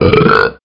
Talking Ben Burp 1